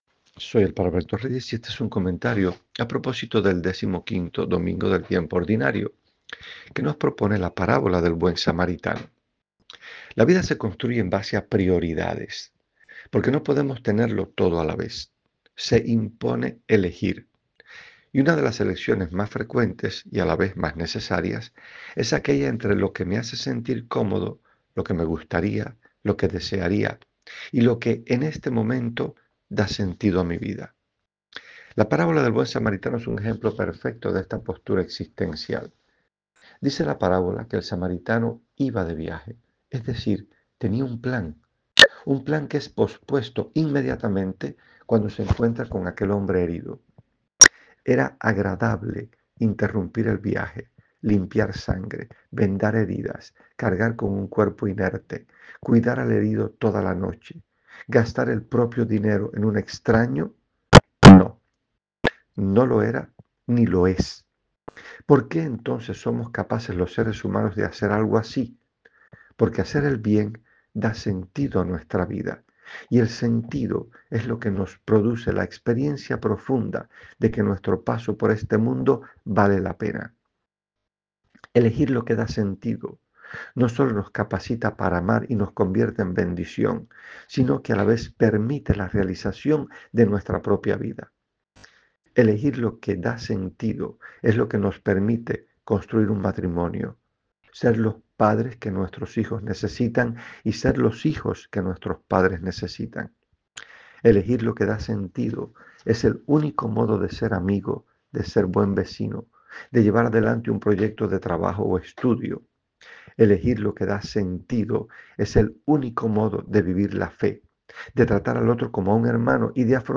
En su Voz: